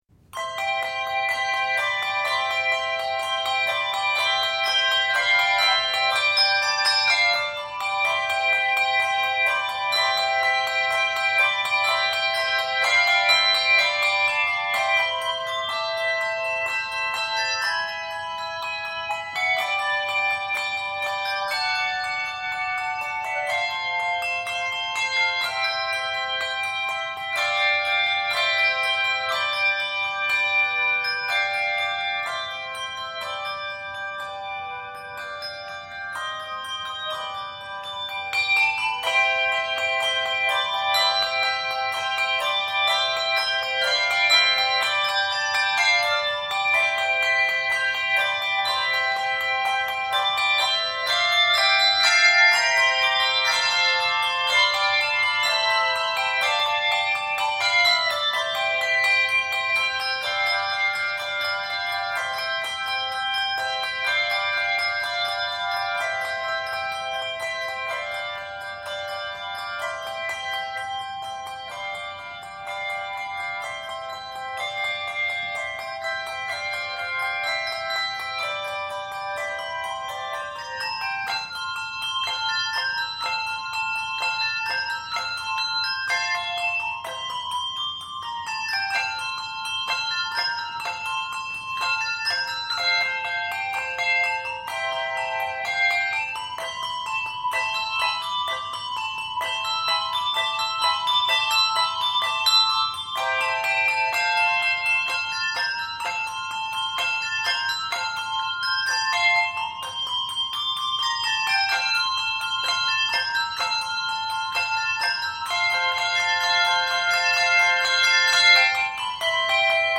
five familiar pieces from the classical repertoire